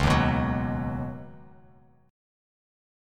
C#7sus2sus4 chord